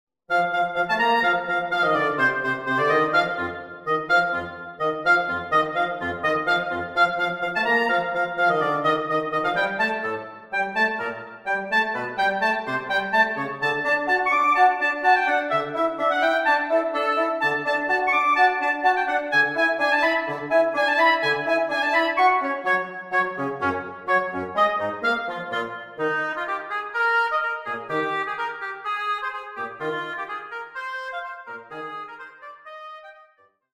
oboe (or clarinet in Bb) and bassoon